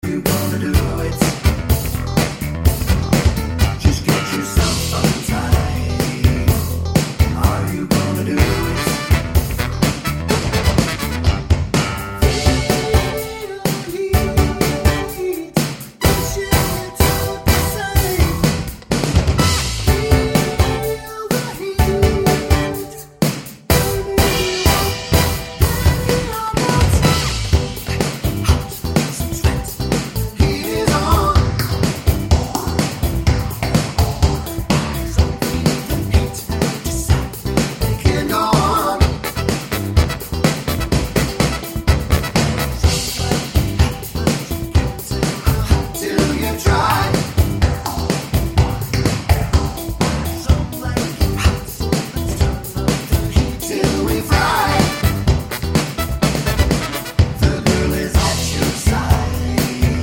Album Version Pop (1980s) 5:05 Buy £1.50